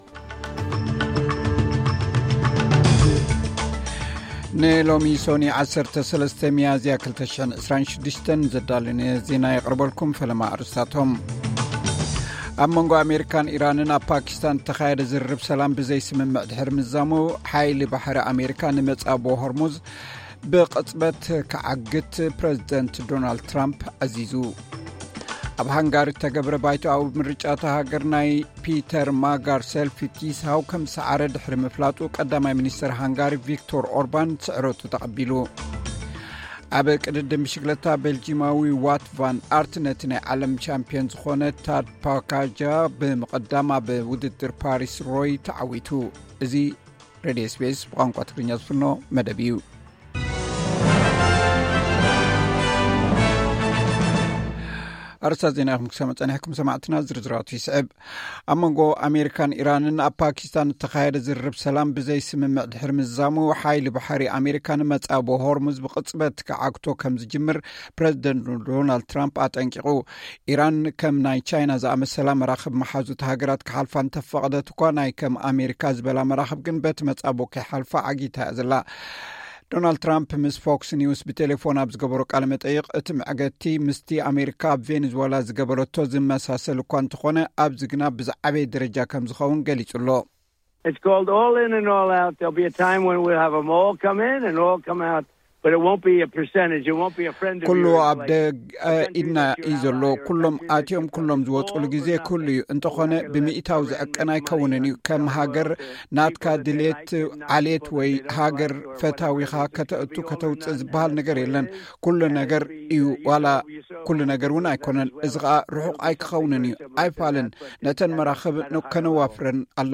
ዕለታዊ ዜና SBS ትግርኛ (13 ሚያዝያ 2026)